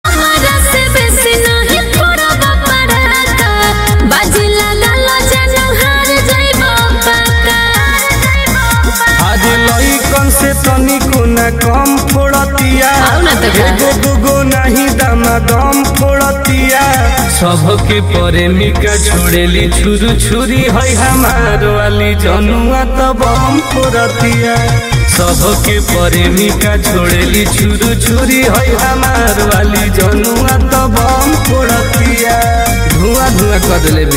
Bhojpuri Ringtones